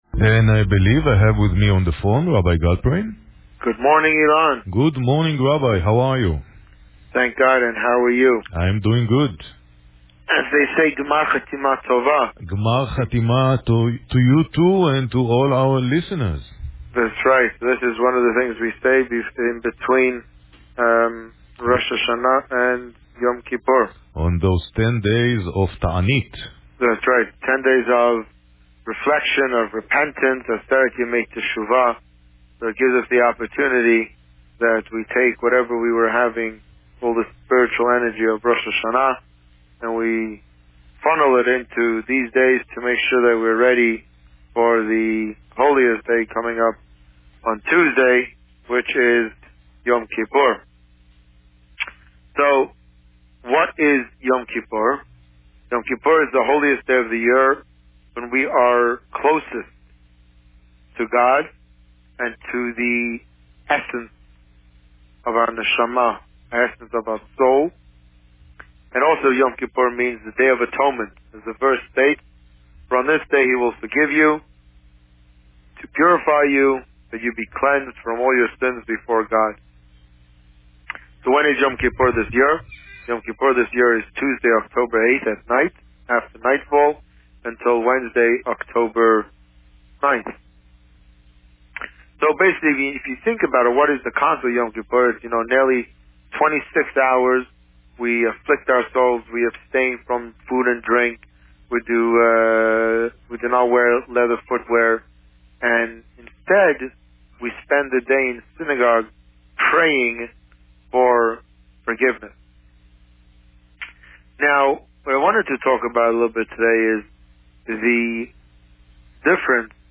Today, the rabbi spoke about the torah portion Vayelech and preparations for the Yom Kippur service. Listen to the interview here.